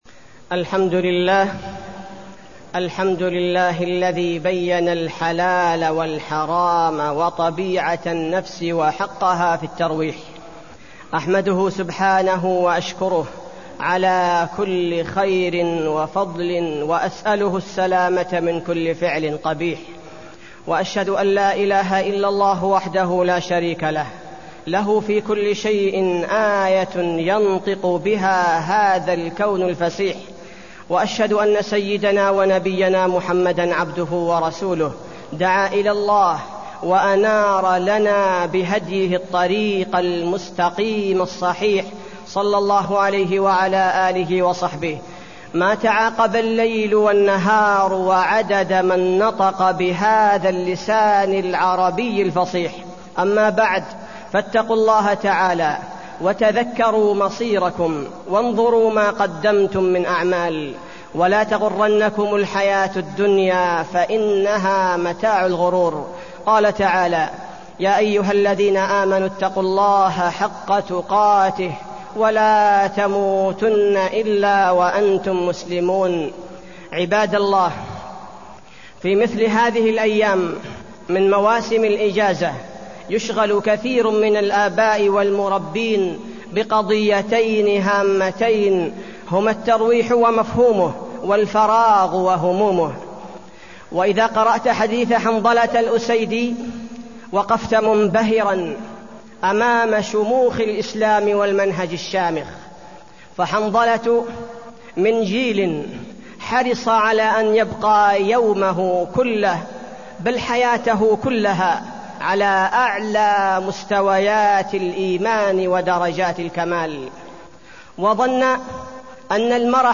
تاريخ النشر ٢١ ربيع الأول ١٤٢١ هـ المكان: المسجد النبوي الشيخ: فضيلة الشيخ عبدالباري الثبيتي فضيلة الشيخ عبدالباري الثبيتي الترويح عن النفس والأجازة الصيفية